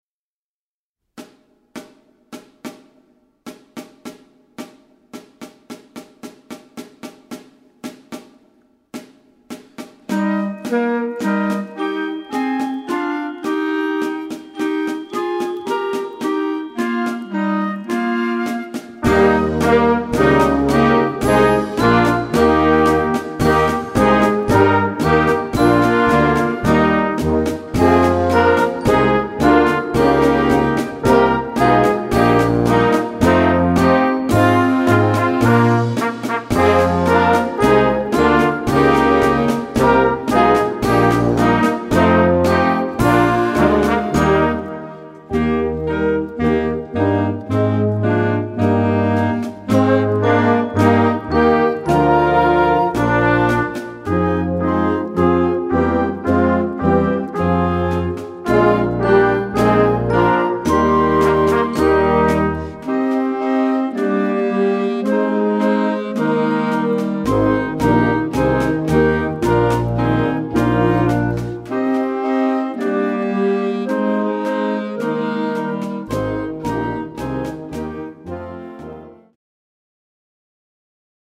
Gattung: Marsch
1:46 Minuten Besetzung: Blasorchester Zu hören auf